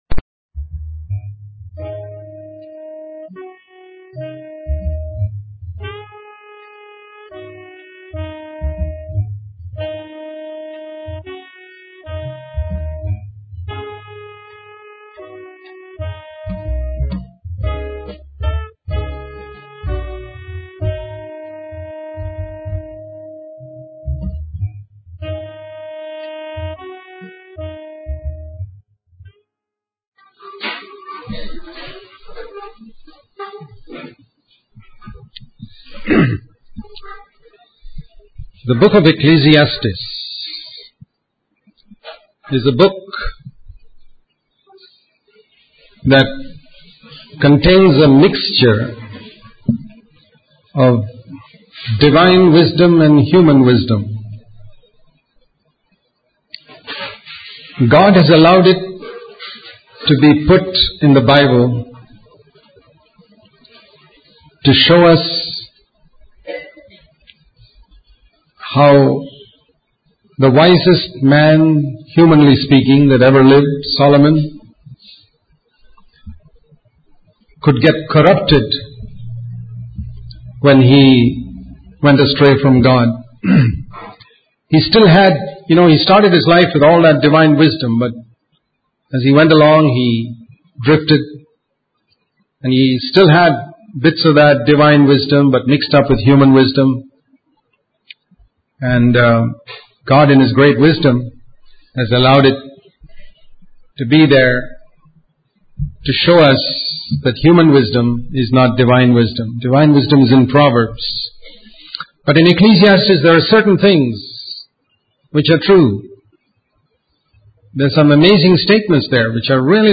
In this sermon, the speaker uses the analogy of a hotel with many rooms to illustrate the Christian life. He emphasizes the importance of having nothing to hide and having a good and loving attitude towards others.